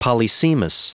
Prononciation du mot polysemous en anglais (fichier audio)